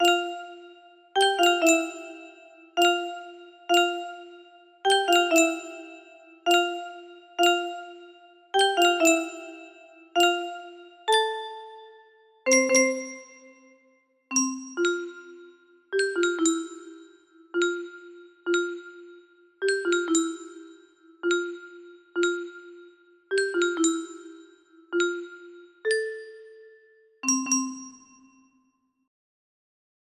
Unknown Artist - Untitled music box melody
Wow! It seems like this melody can be played offline on a 15 note paper strip music box!